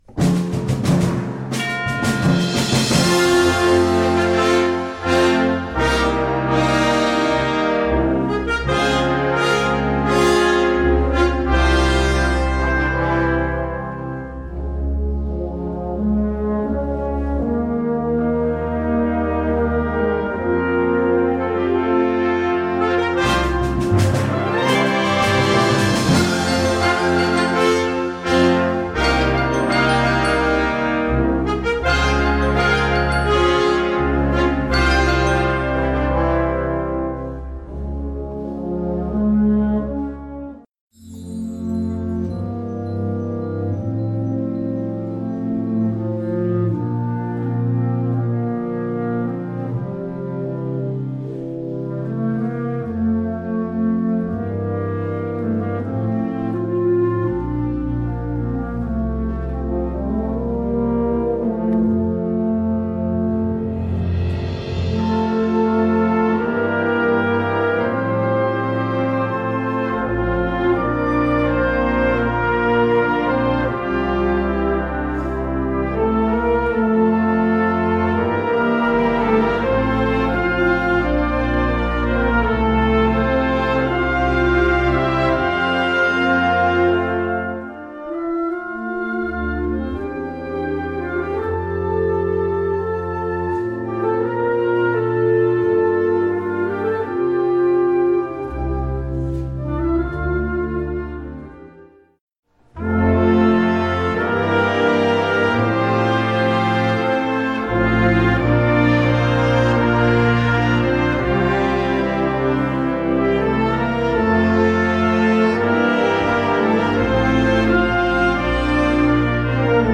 Categorie Harmonie/Fanfare/Brass-orkest
Subcategorie Openingsmuziek
Bezetting Ha (harmonieorkest)